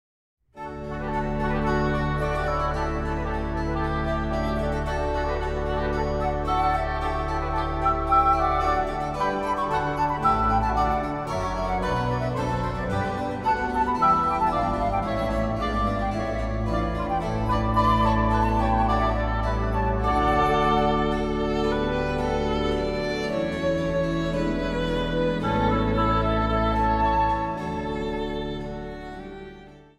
Instrumentaal | Dwarsfluit
Instrumentaal | Hobo
Instrumentaal | Synthesizer
Instrumentaal | Viool